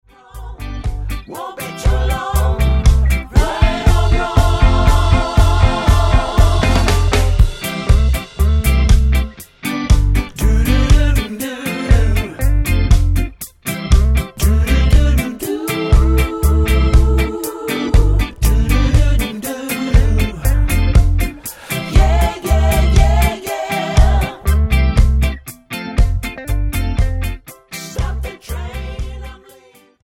Tonart:C mit Chor